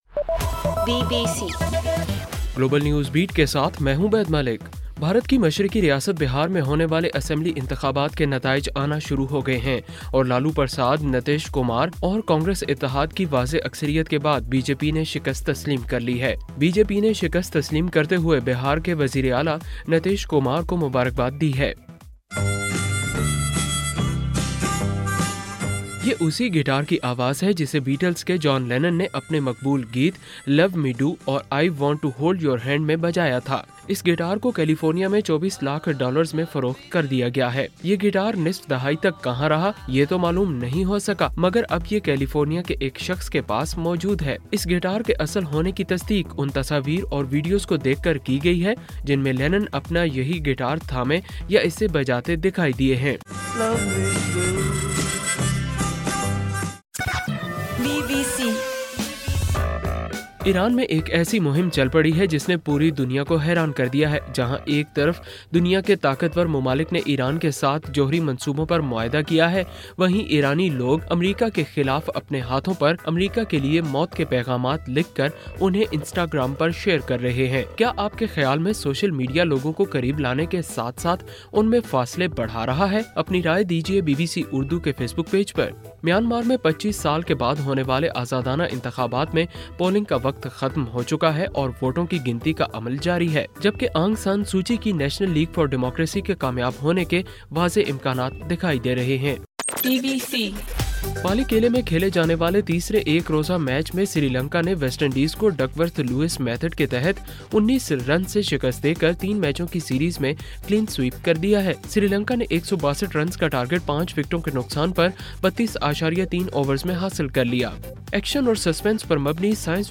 نومبر 8: رات 9 بجے کا گلوبل نیوز بیٹ بُلیٹن